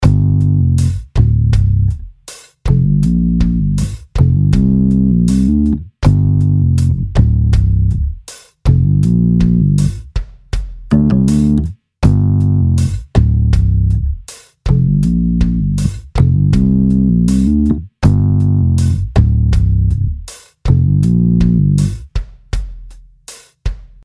Die Bässe klingen durchweg sehr voll, angenehm brummig und rund, teils mit markantem Attack.